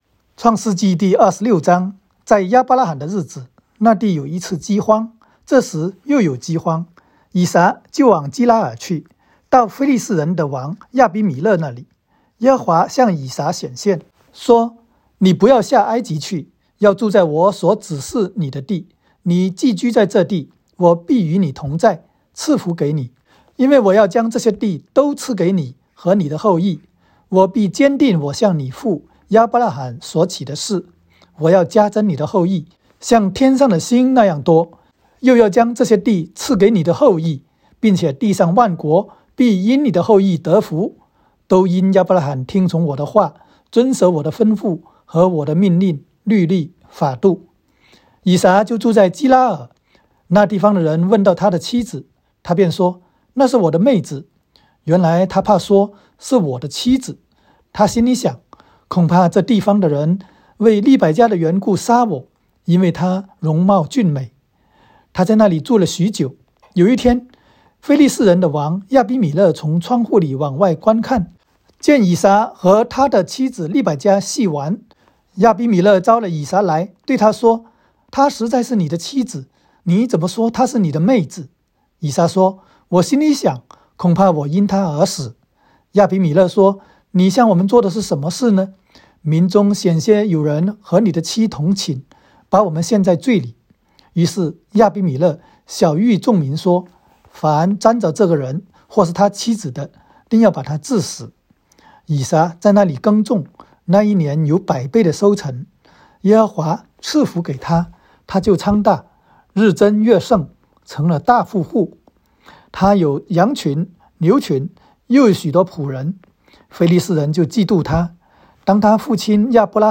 创26（经文-国）.m4a